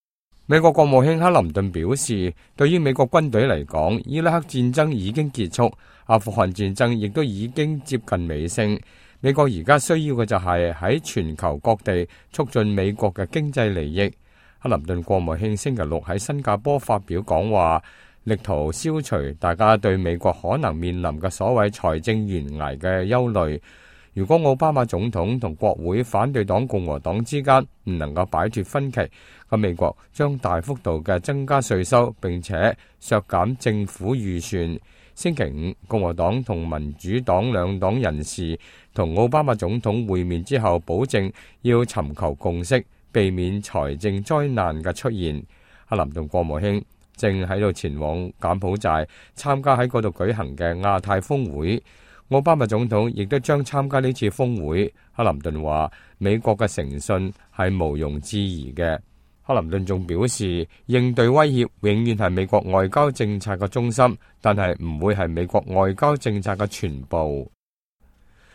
美國國務卿克林頓星期六在新加坡發表講話